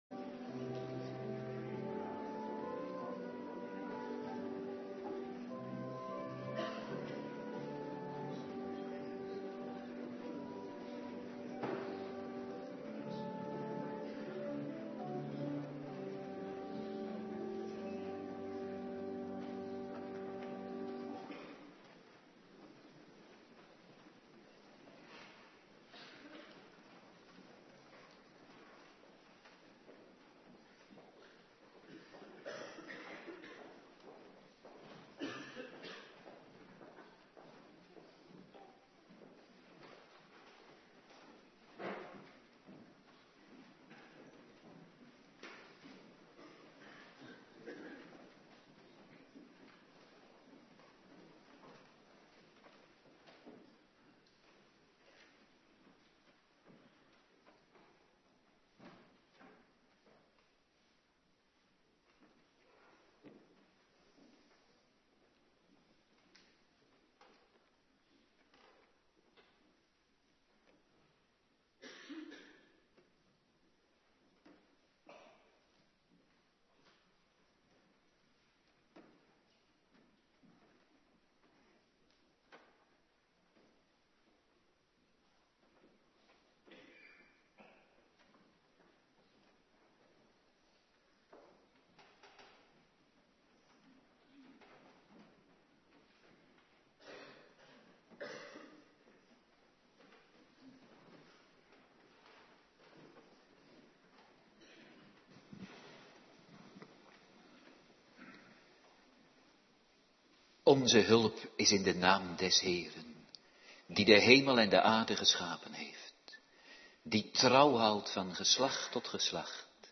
Morgendienst
09:30 t/m 11:00 Locatie: Hervormde Gemeente Waarder Agenda
Doopdienst